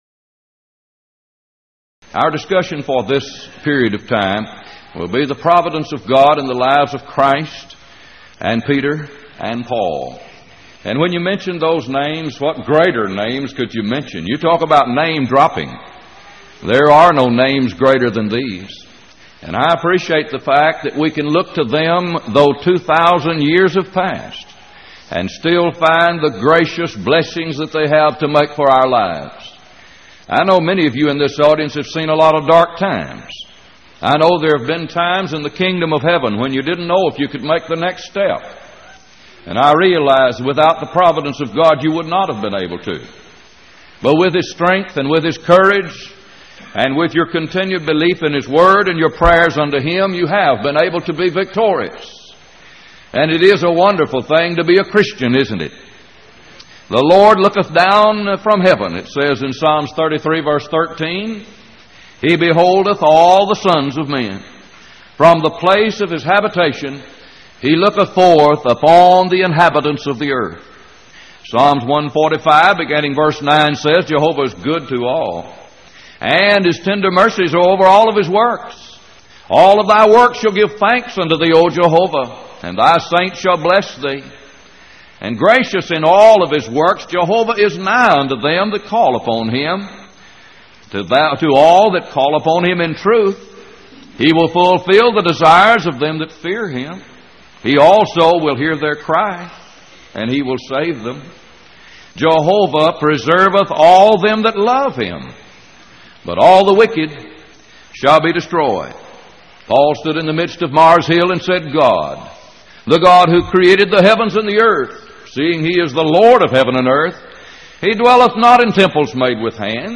Event: 1989 Power Lectures Theme/Title: The Providence of God